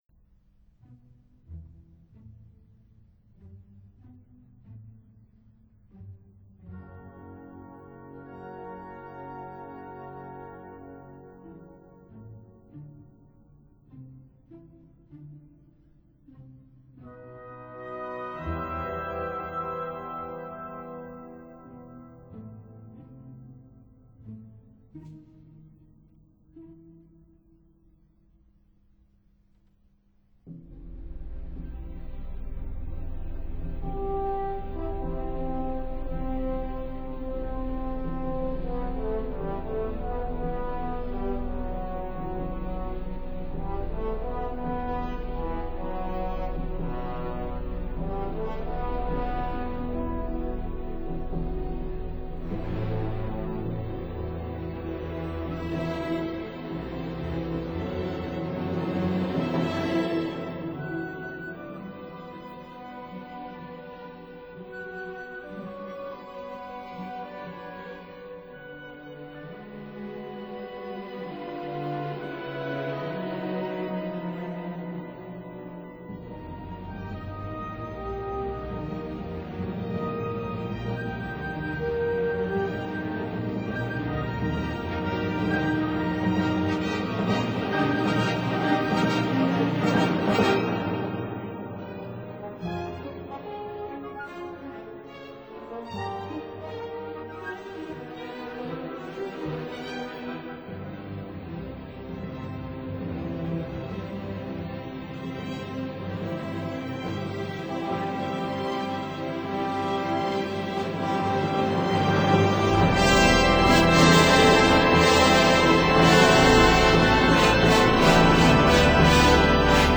for organ & orchestra